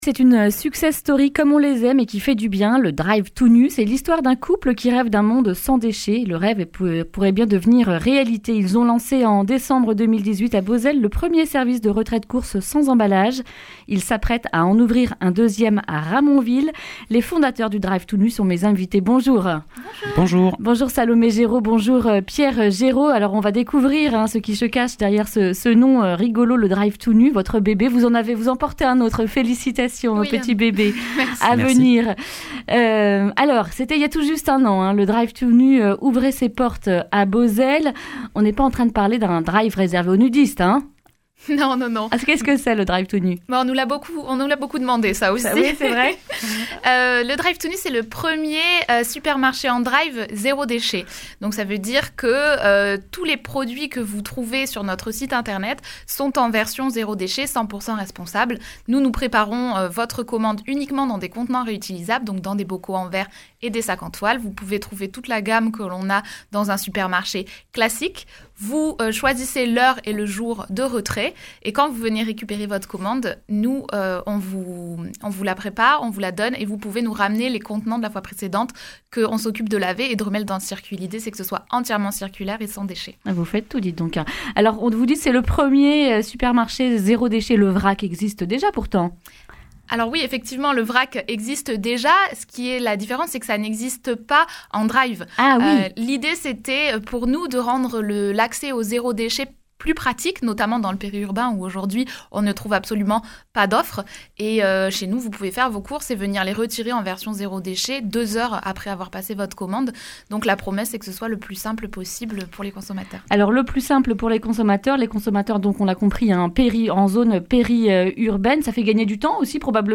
Accueil \ Emissions \ Information \ Régionale \ Le grand entretien \ Le Drive tout nu, premier drive zéro déchet, s’agrandit au sud de Toulouse !